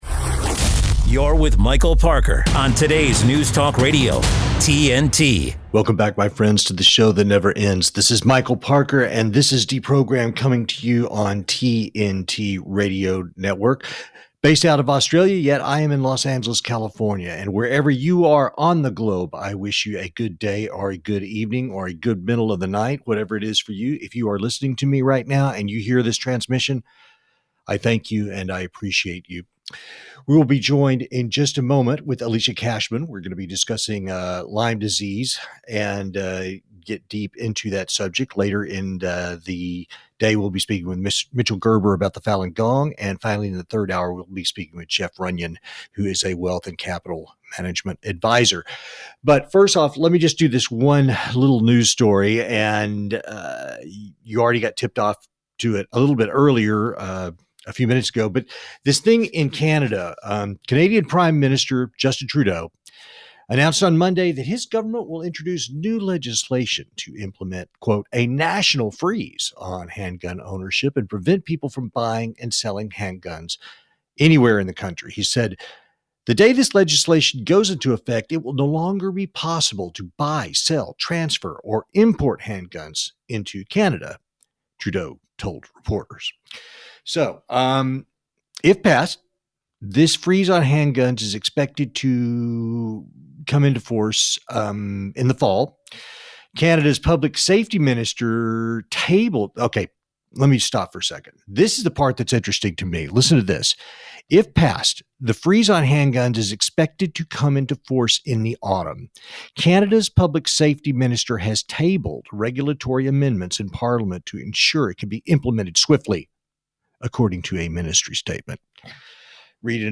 Interview About Lyme Disease on TNT Radio
Interview here (Scroll to 4:45 to bypass other news):